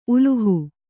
♪ uluhu